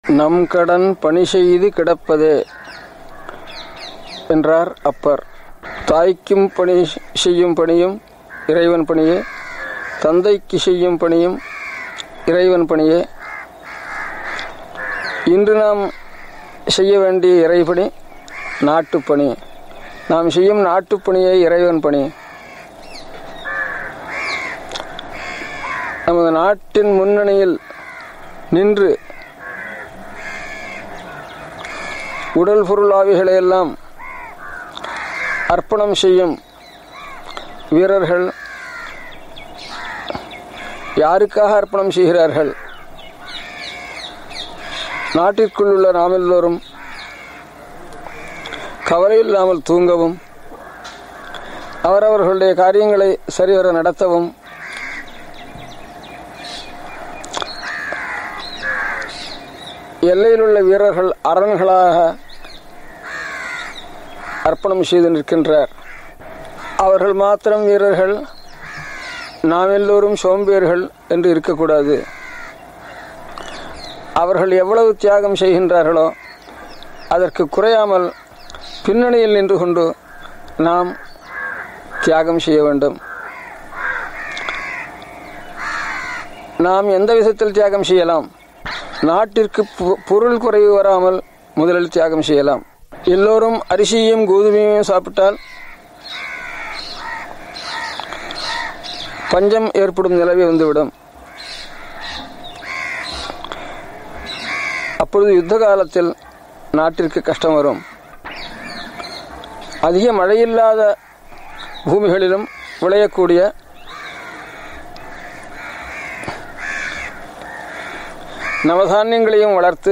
On this Occasion I share with you the GOLDEN VOICE OF SRI PARAMACHARYA in TAMIL and SANSKRIT, the message He gave to the Nation during Chinese Aggression on India (1962).
Paramacharya’s Message in Tamil in His own voice:
Message-in-Tamil-by-His-Holiness-Sankaracharya-of-Kanchi-Kamakoti-Peetam-during-external-invasion.mp3